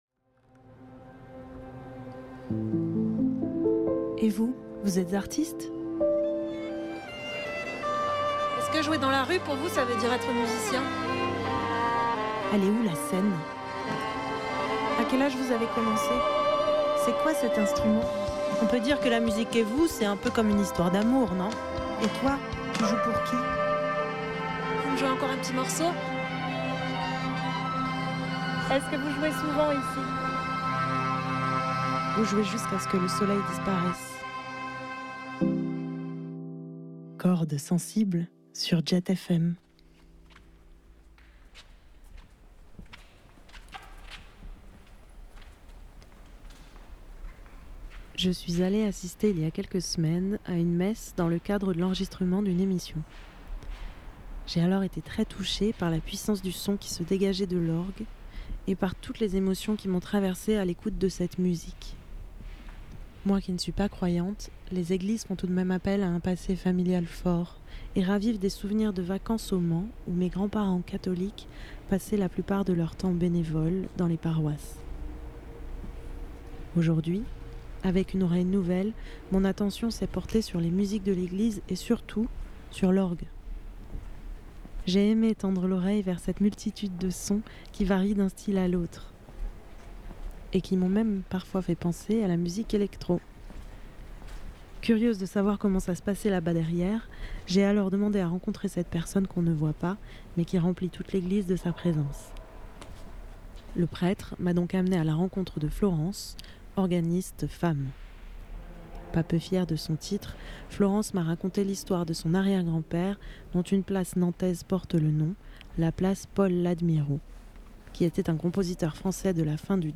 Nous sommes allées à la découverte des entrailles de l’orgue, cet instrument imposant, que j’ai toujours rêvé de pouvoir toucher un jour.